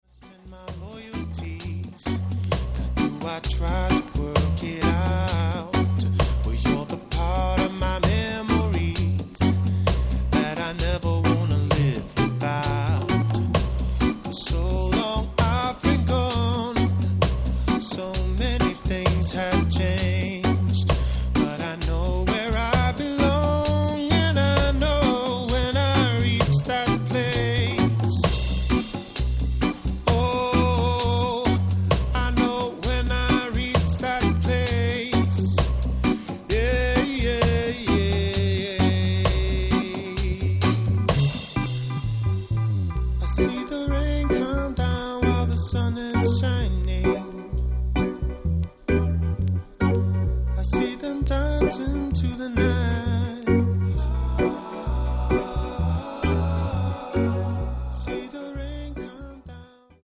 Dope remix